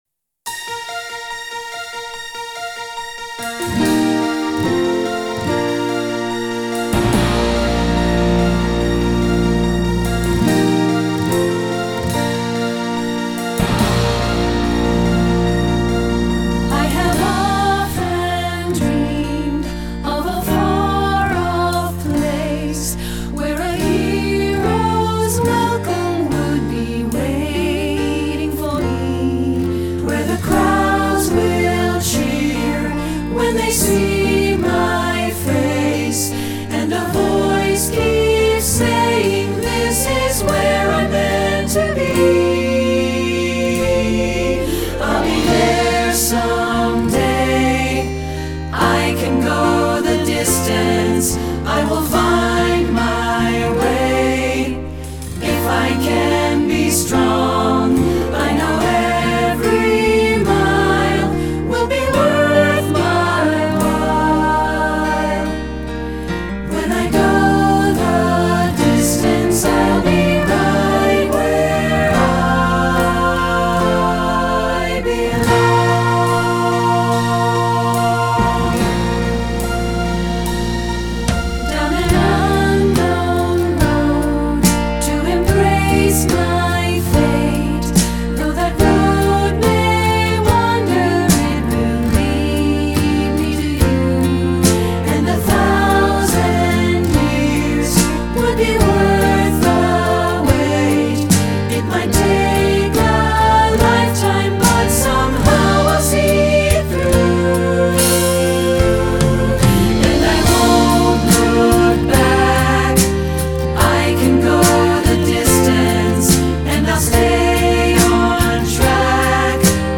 Choral Movie/TV/Broadway
3 Part Mix
3-Part Mixed Audio